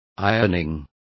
Complete with pronunciation of the translation of ironing.